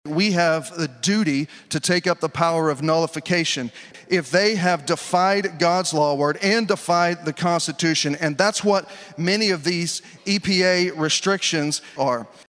CLICK HERE to listen to commentary from Senator Dusty Deavers.